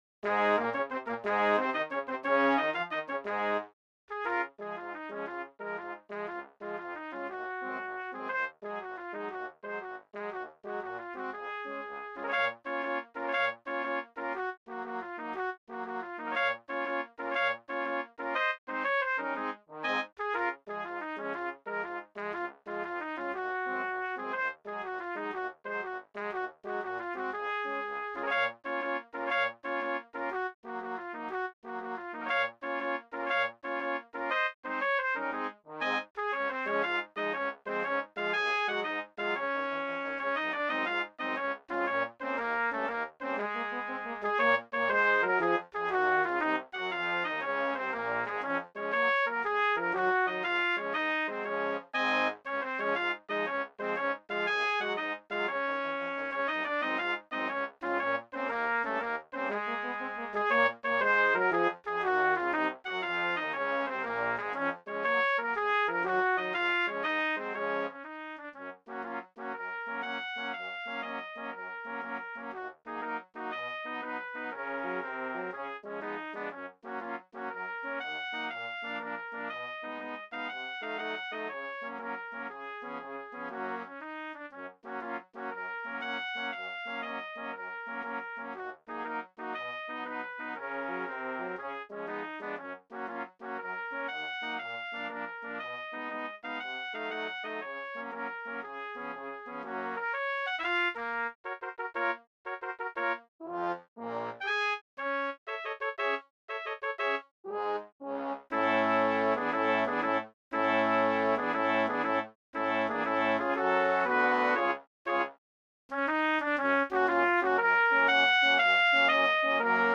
BRASS QUINTET
MARCH FOR STANDARD BRASS QUINTET